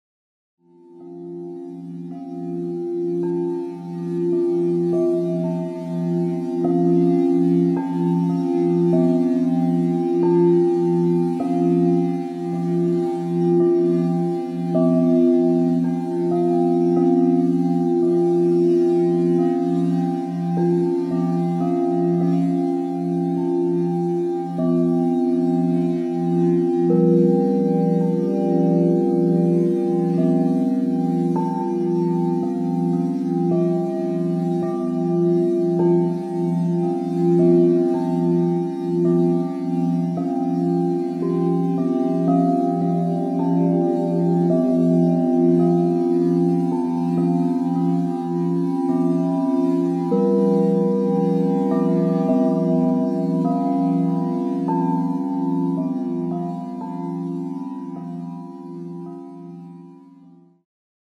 Diese CD besitzt sehr tiefe und sehr hohe Töne.